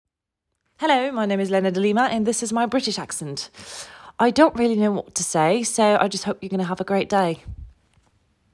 Accent UK